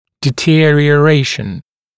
[dɪˌtɪərɪə’reɪʃ(ə)n][диˌтиэриэ’рэйш(э)н]ухудшение